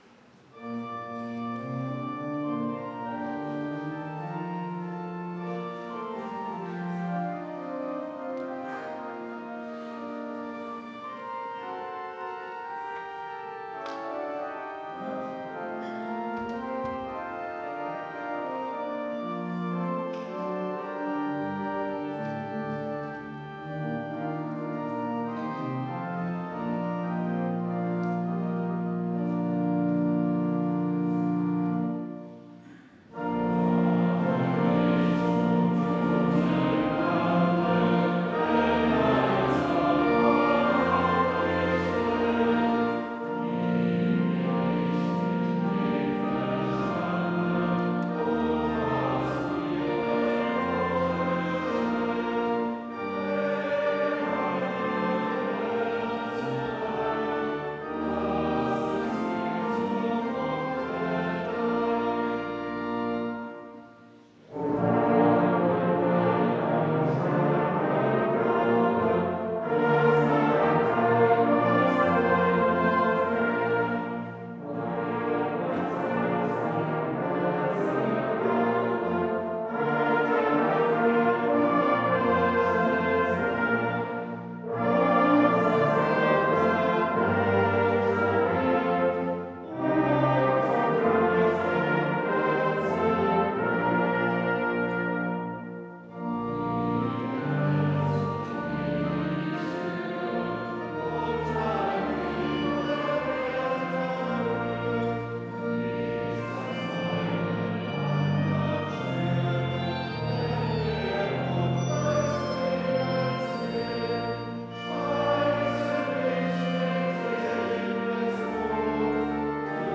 Tut mir auf die schöne Pforte... (LG 200,4-6) Ev.-Luth. St. Johannesgemeinde Zwickau-Planitz
Audiomitschnitt unseres Gottesdienstes vom Kirchweihfest 2024